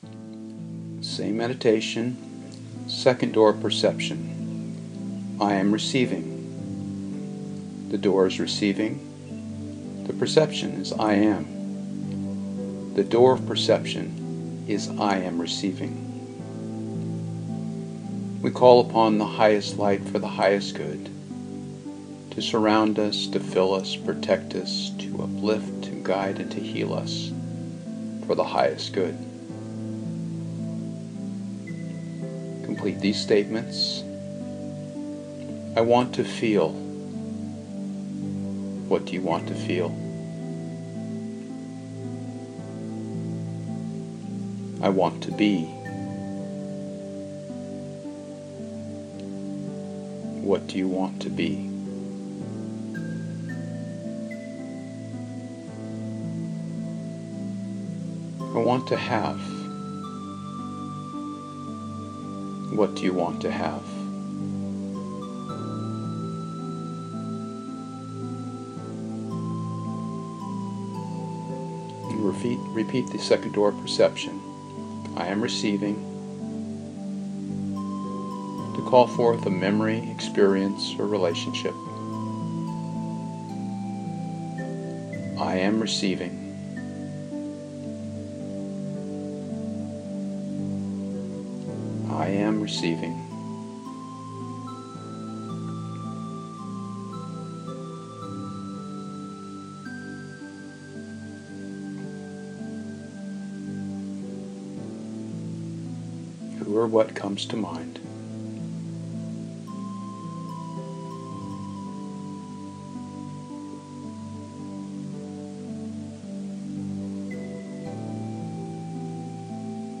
1-1-2-I-am-Receiving-with-music-1.mp3